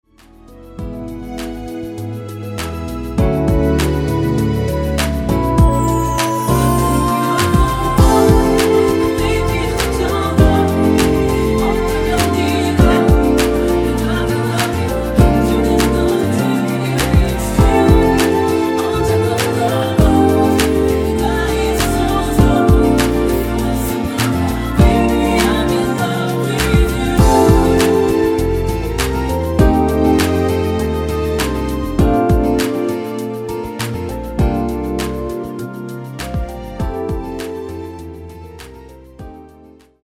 (-2) 내린 코러스 포함된 MR 입니다.(미리듣기 나오는 부분 코러스 입니다.)